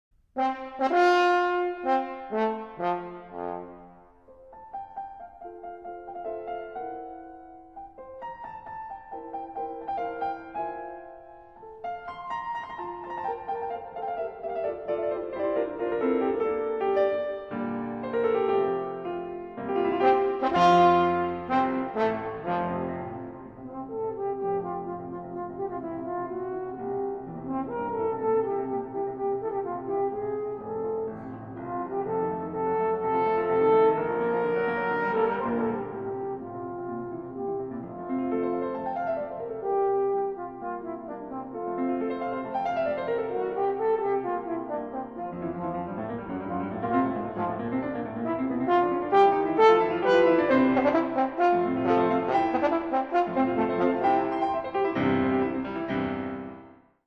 Sonata for Horn and Piano, Op 17